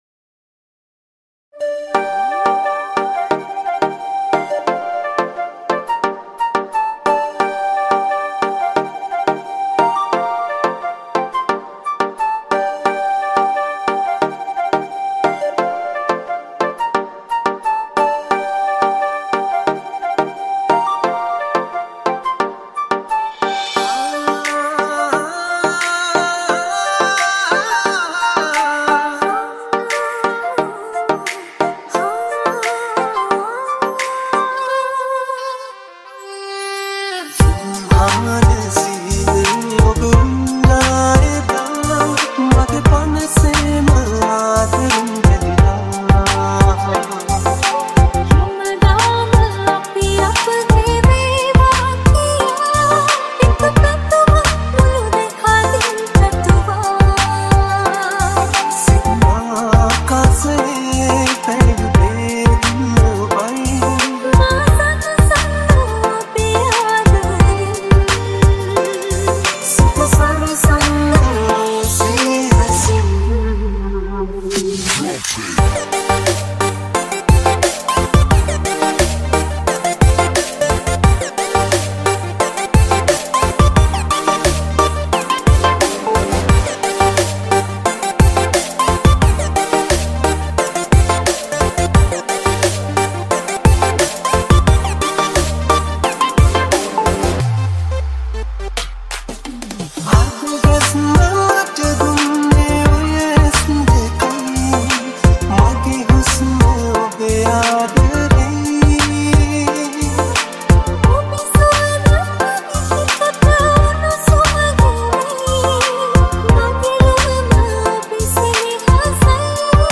Sinhala Remix Song | Sinhala DJ Remixed